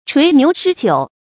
槌牛釃酒 注音： ㄔㄨㄟˊ ㄋㄧㄨˊ ㄕㄞ ㄐㄧㄨˇ 讀音讀法： 意思解釋： 殺牛濾酒。謂備肴酒以燕享。